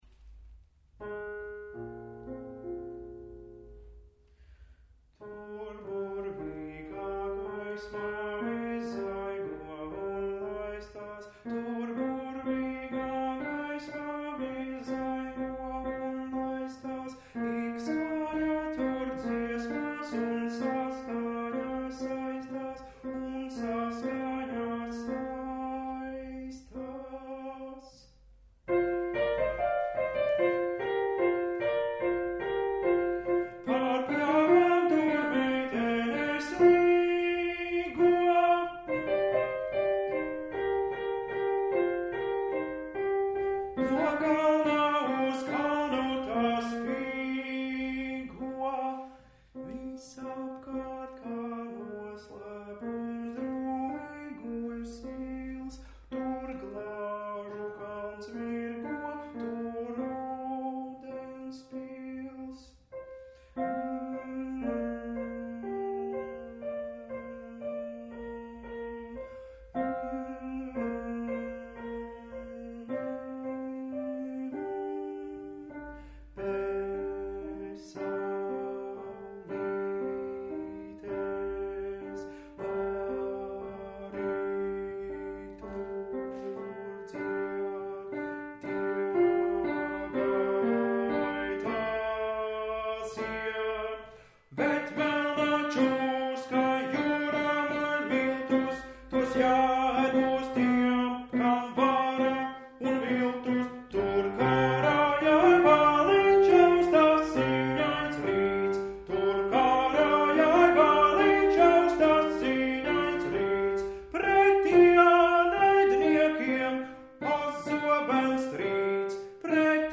Otrais tenors – Koris MASKA